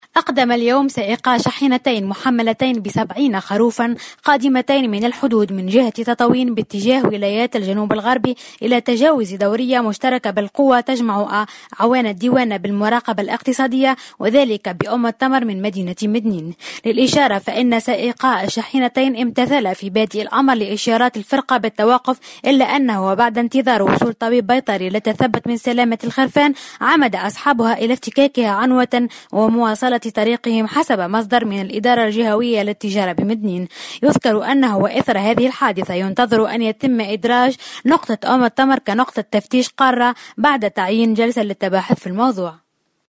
مراسلتنا في الجهة